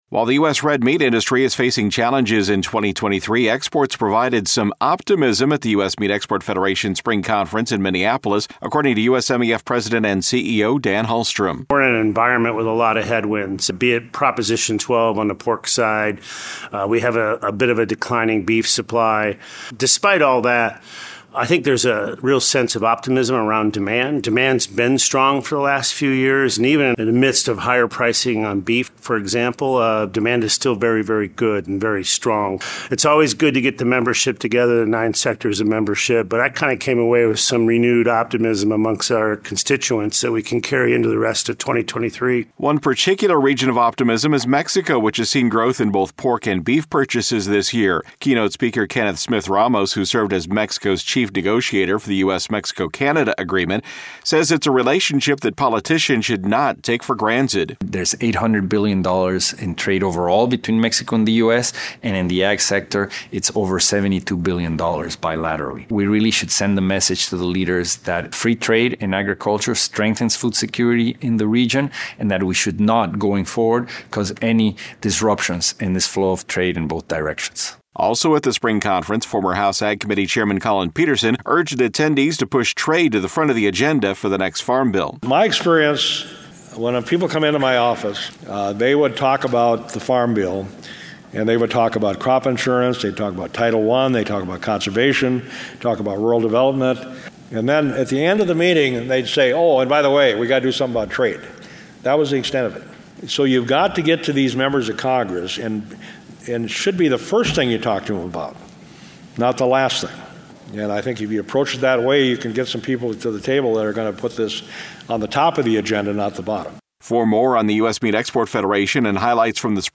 As the U.S. red meat industry faces a number of challenges, exports provided a bright spot as U.S. Meat Export Federation members gathered in Minneapolis May 24-26, 2023, for the organization’s Spring Conference.